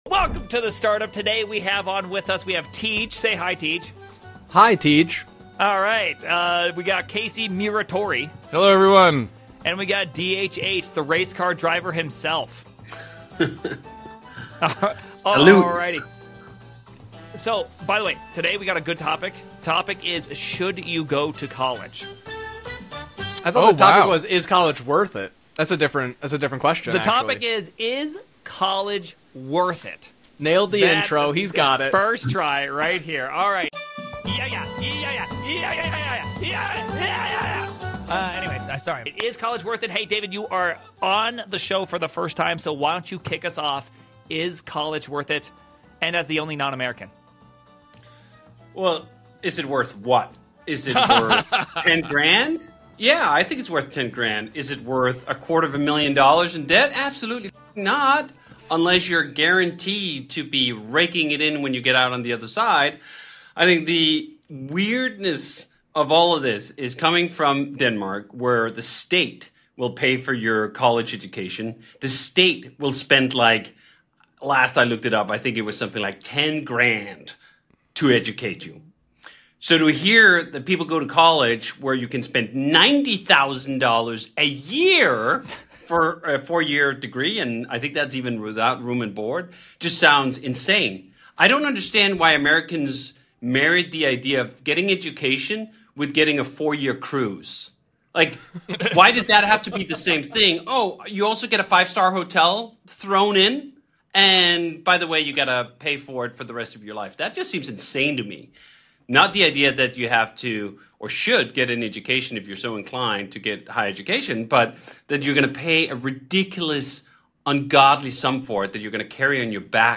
Welcome to The Standup – where developers, creators, and founders debate the big questions. In this episode, we sit down with David Heinemeier Hansson (DHH), the creator of Ruby on Rails and Basecamp co-founder.